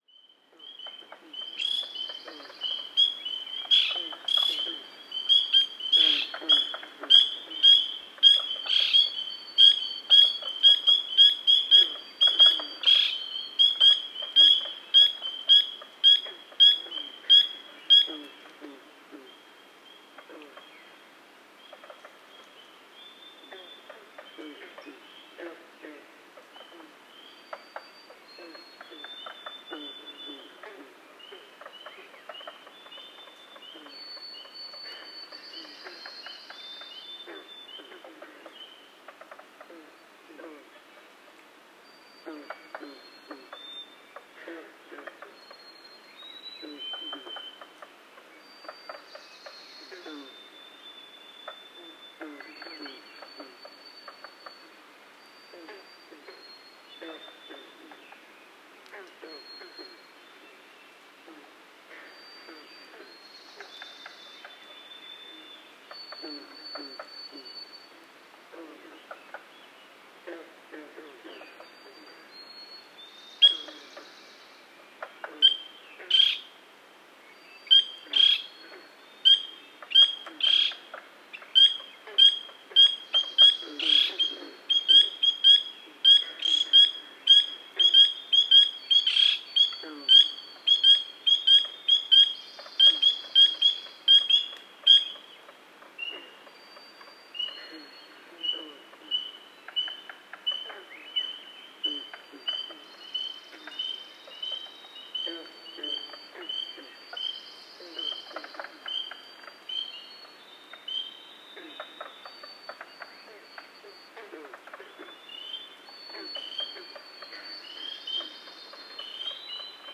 Rainette crucifère – Pseudacris crucifer
Vocalisation de la Rainette crucifère au sein de ce magnifique concert d'amphibiens et d'oiseaux au lever du jour. Extrait des chants du matin de la Forêt Ouareau.
a7617-loiseau-son-rainette-crucifere-foret-ouareau-quebec.m4a